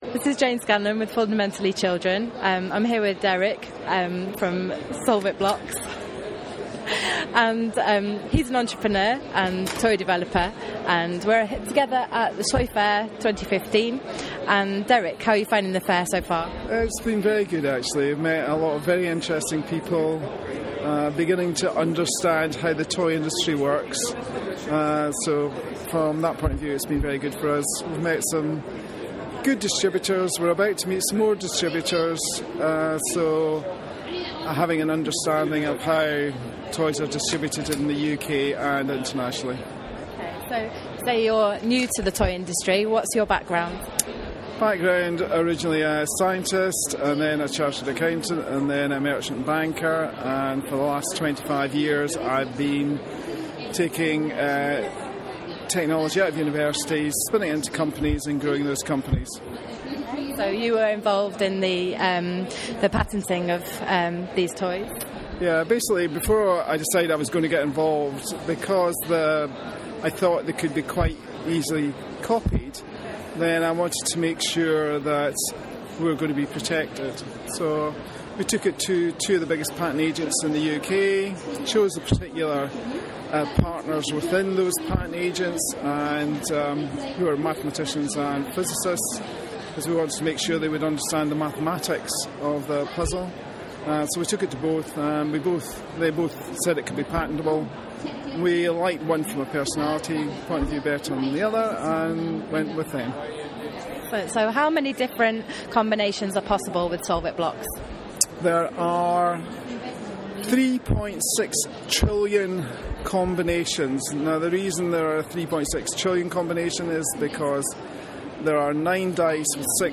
At the 2015 London Toy Fair, Fundamentally Children was proud to showcase Solve-It Blocks, a fun and educational puzzle for all ages.
Fundamentally Children Interview